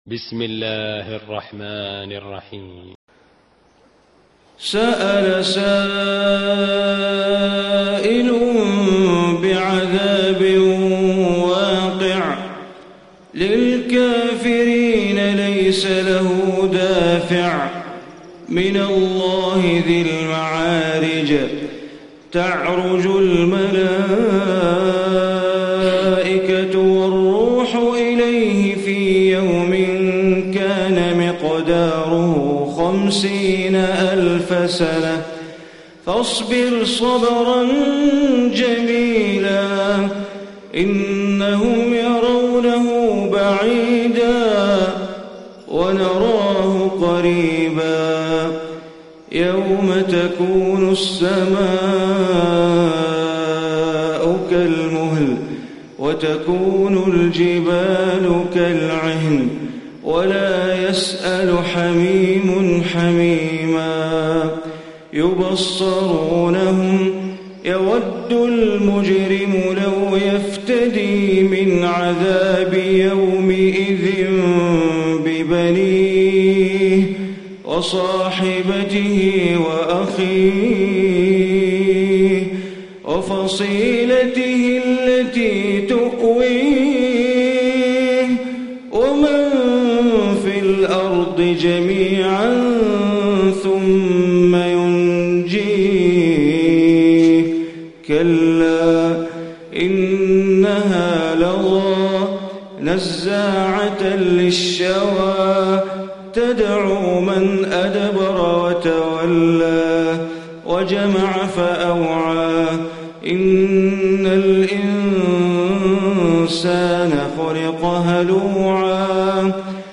Surah Maarij Recitation by Sheikh Bandar Baleela
Surah Maarij, listen online mp3 tilawat / recitation in Arabic recited by Imam e Kaaba Sheikh Bandar Baleela.